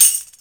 176UK2TAMB-R.wav